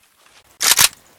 ak_unjam.ogg